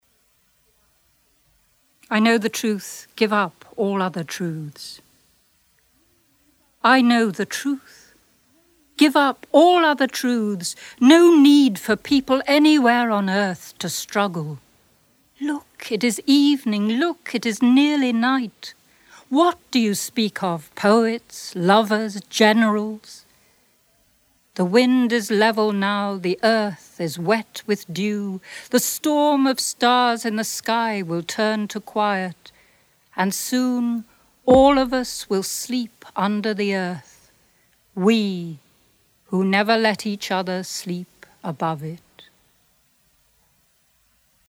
The Poet Speaks from Poems on the Underground Audiobook 1994
You took away all the oceans and all the room by Osip Mandelstam read by Ruth Fainlight